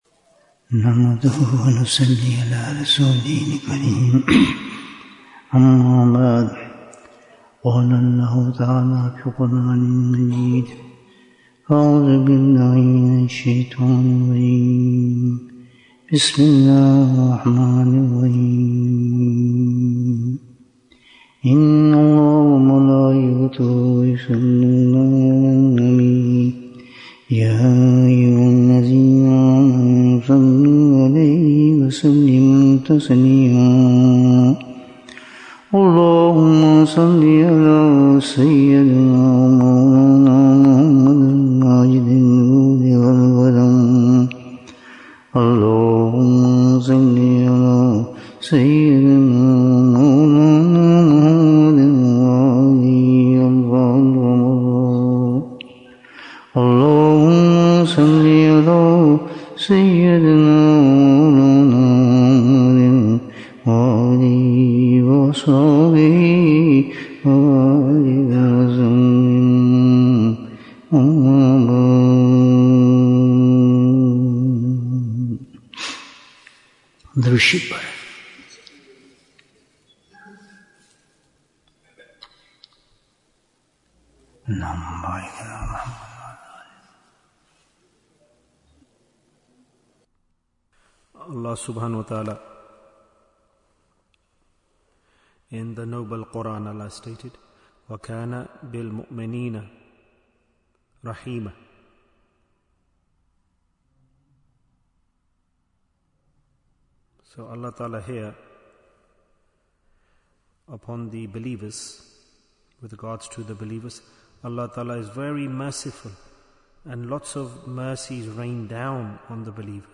Jewels of Ramadhan 2026 - Episode 25 Bayan, 42 minutes5th March, 2026